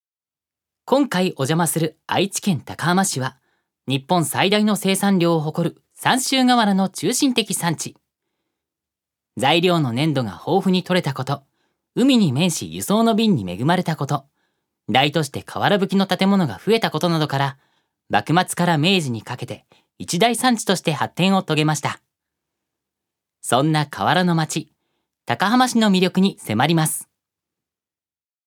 預かり：男性
ナレーション１